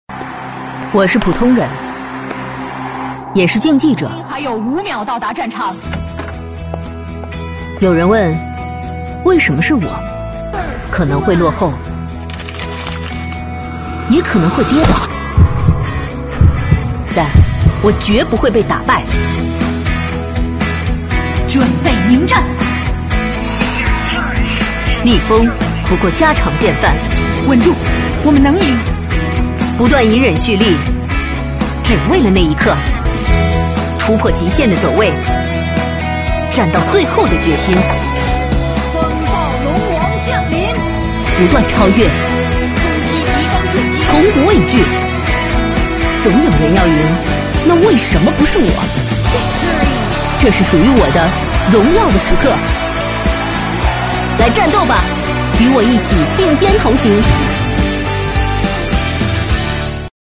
女35-形象片【TGA王者女子赛-感染力强】
女35-磁性甜美 激情力度
女35-形象片【TGA王者女子赛-感染力强】.mp3